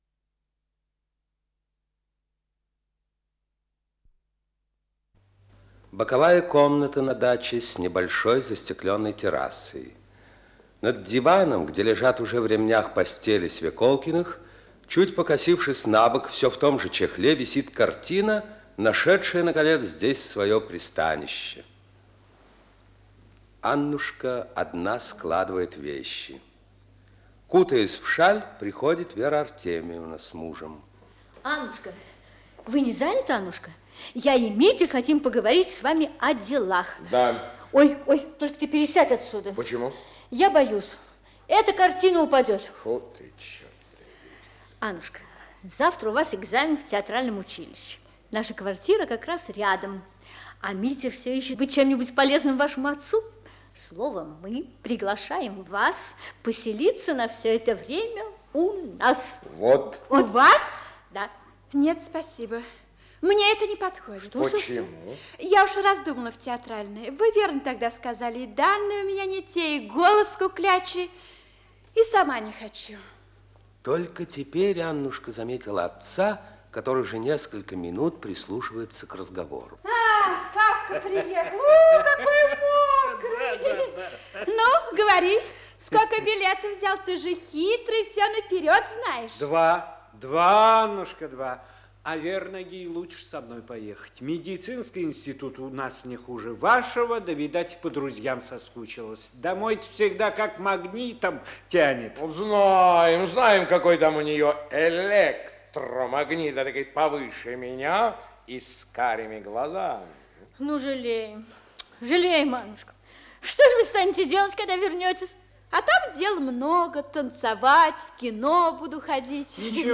Исполнитель: Артисты театра им. В. Маяковского
Название передачи Театр у микрофона, "Обыкновенный человек" Подзаголовок Радиокомпозиция спектакля театра им. Маяковского